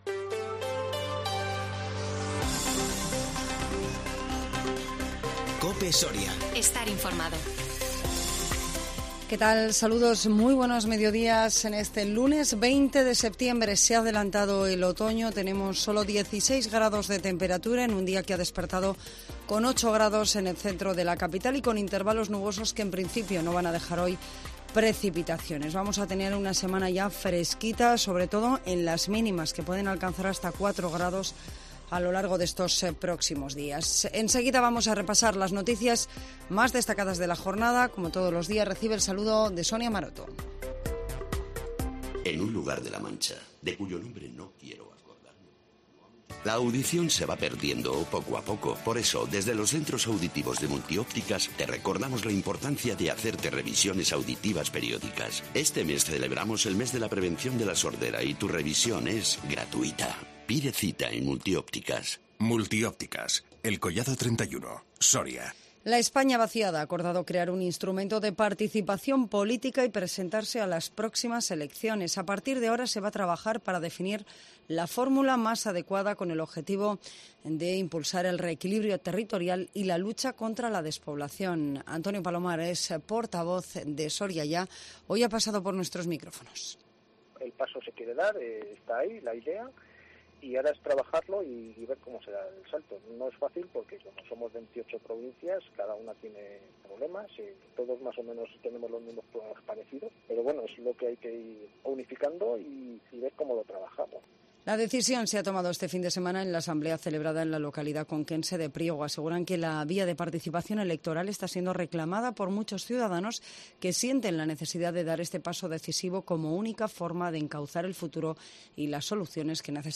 INFORMATIVO MEDIODÍA 20 SEPTIEMBRE 2021